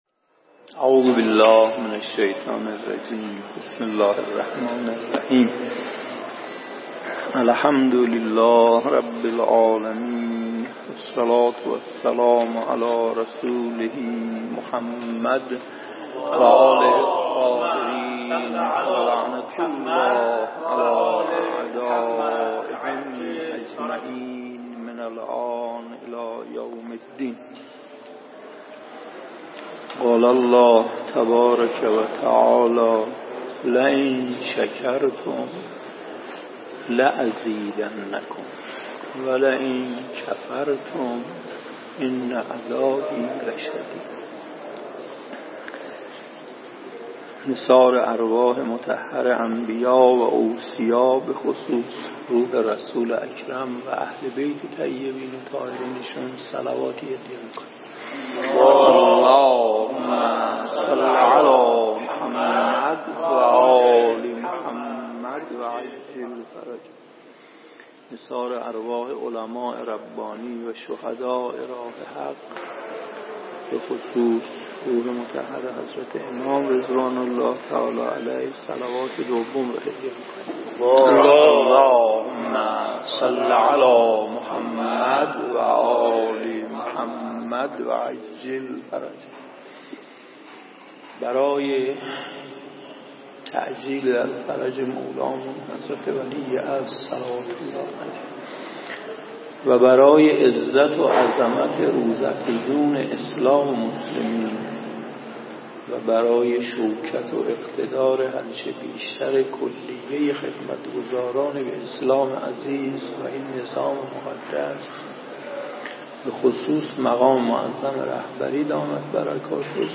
درس الاخلاق
🔰 با مرثیه سرایی